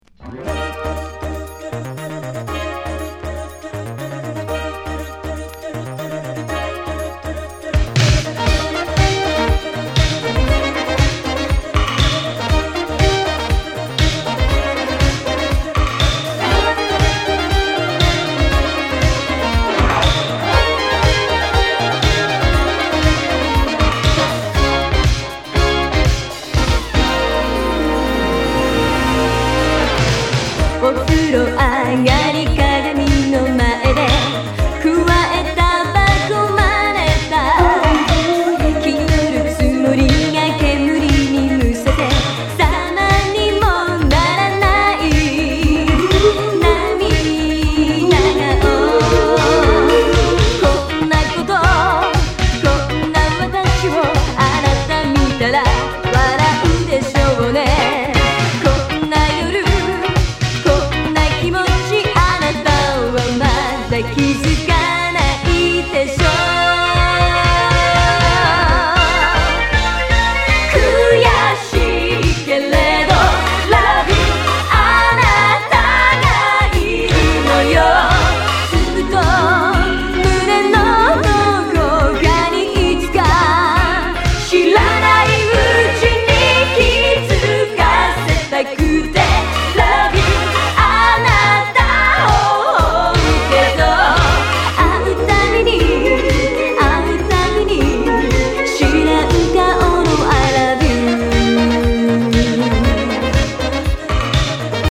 B面はよりバシバシのビートの